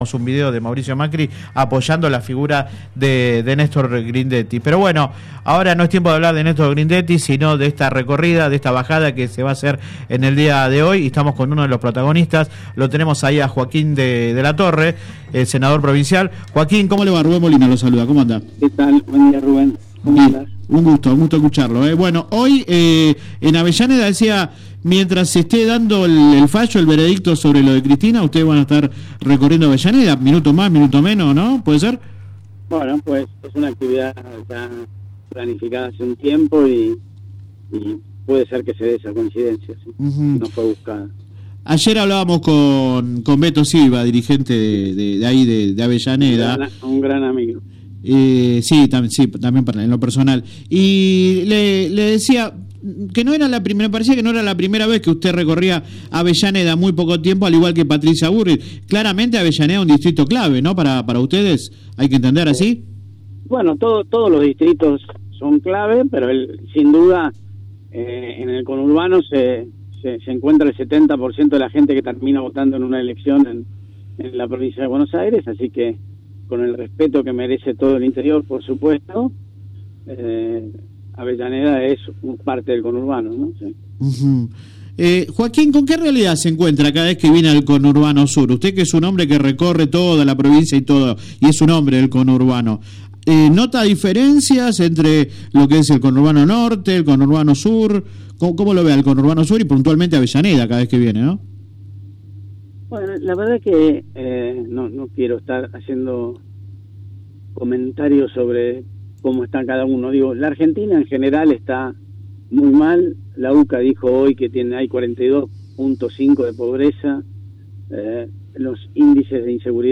De todas formas, el legislador provincial habló en exclusiva con el programa radial  Sin Retorno (lunes a viernes de 10 a 13 por GPS El Camino FM 90 .7 y AM 1260).